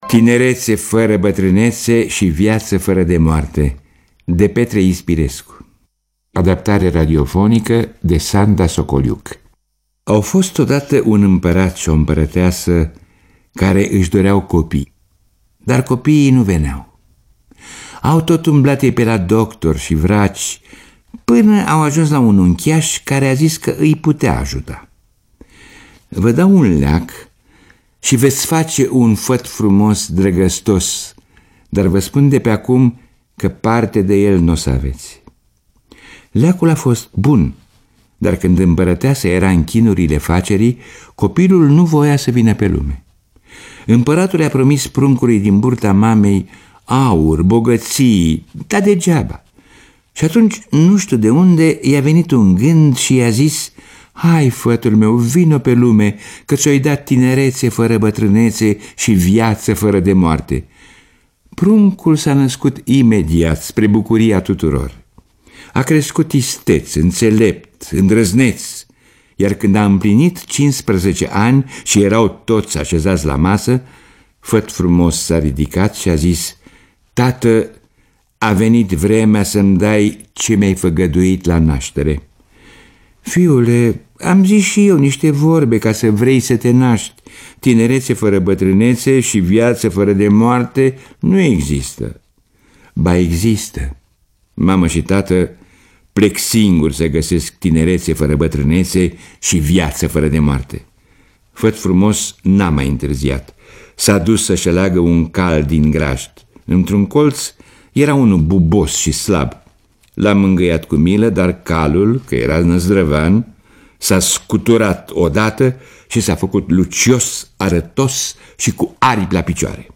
Lectura: Victor Rebengiuc.